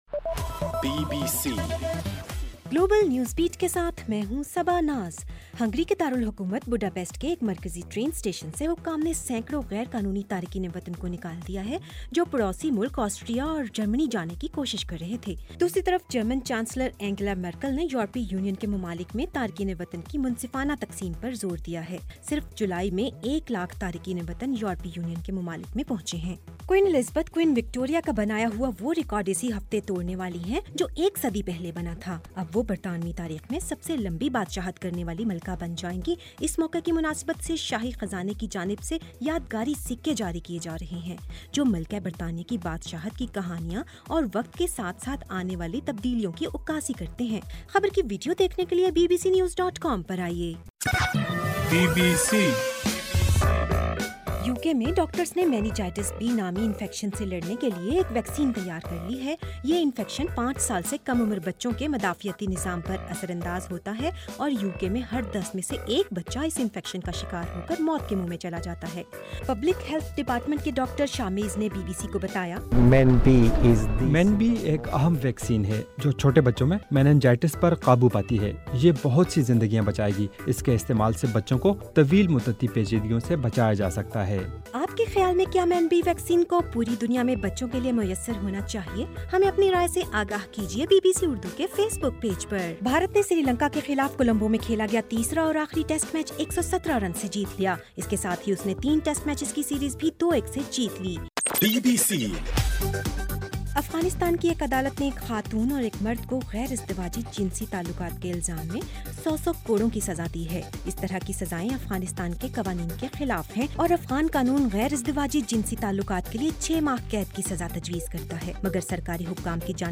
ستمبر 1:رات 8 بجے کا گلوبل نیوز بیٹ بُلیٹن